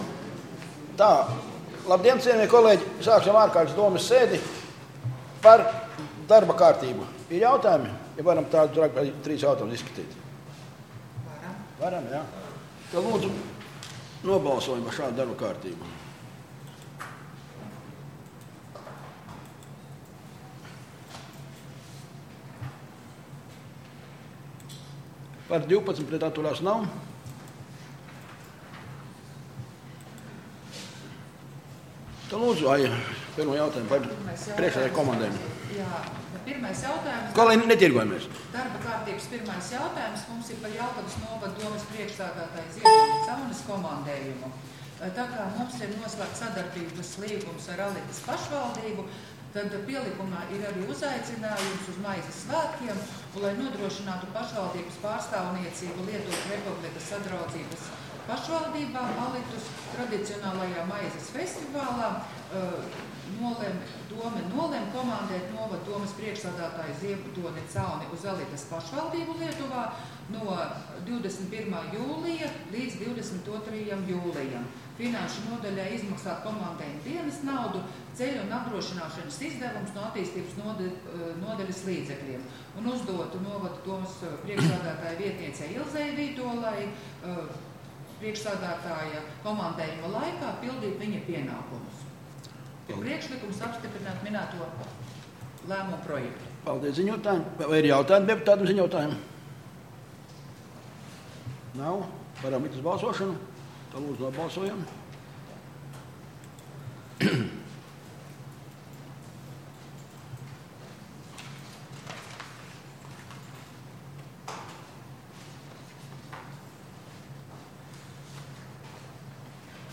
Domes ārkārtas sēde Nr. 9